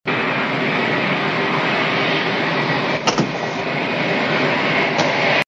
• Plane engine humming.wav
[Plane-engine-humming-Sound-Effect]_W3r.wav